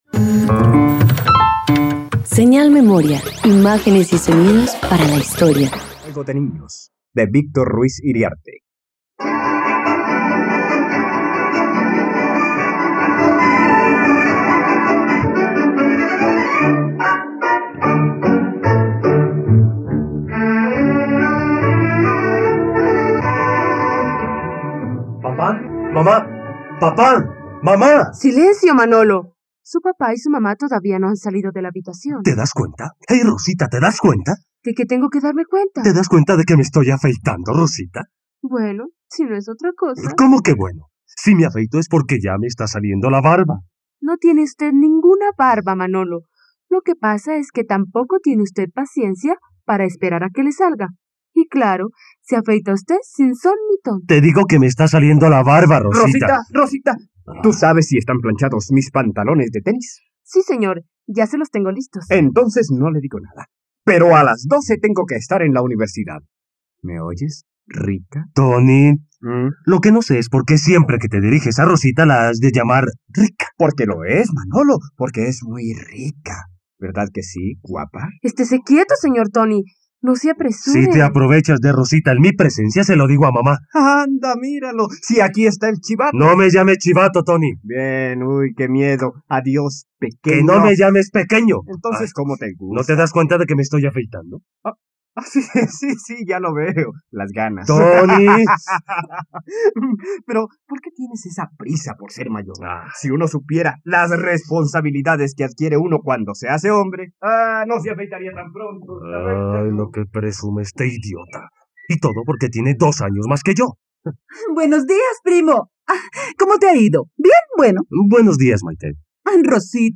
Juego de niños - Radioteatro dominical | RTVCPlay
Sinopsis Una adaptación para radio de la obra “Juego de niños" del dramaturgo español Víctor Ruiz Iriarte. Una historia que muestra varias problemáticas familiares a causa de las infidelidades e inestabilidad emocional de una pareja de esposos.